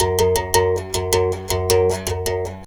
91 BERIMBAU.wav